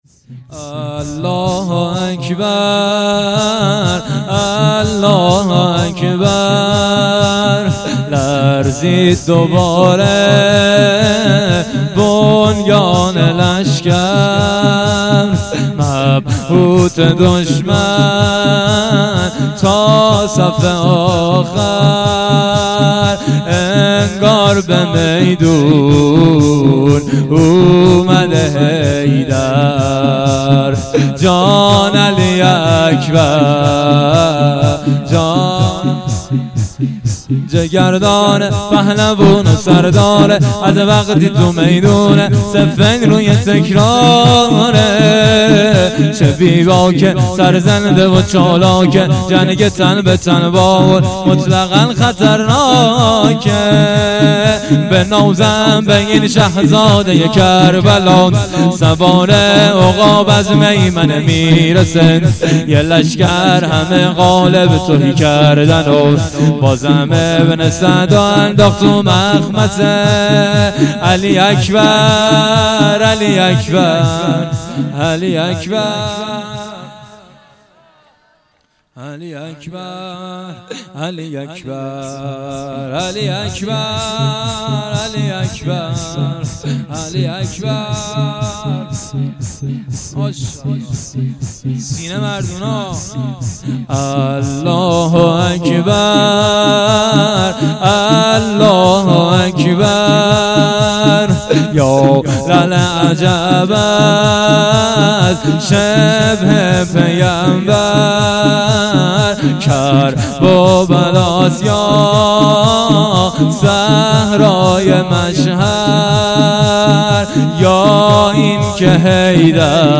شور - لرزید دوباره بنیان لشکر
شب هشتم - دهه اول محرم 1400